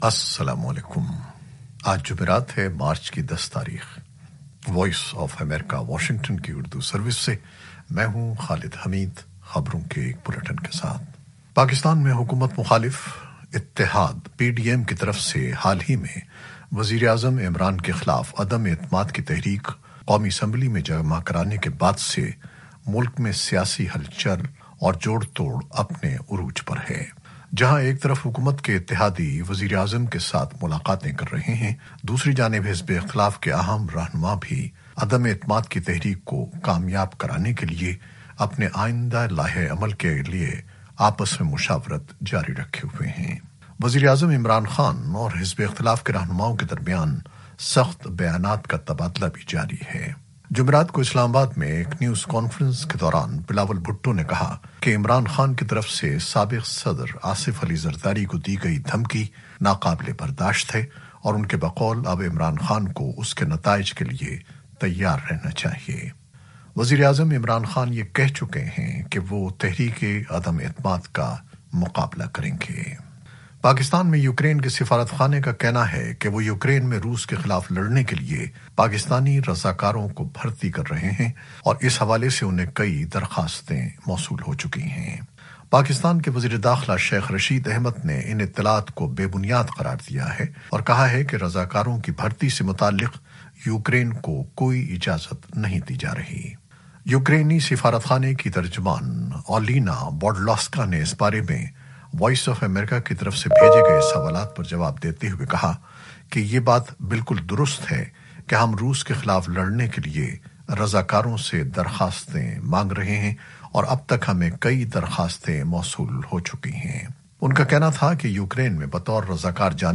نیوز بلیٹن 2021-10-03
شام سات بجے کی خبریں خالد حمید کے ساتھ۔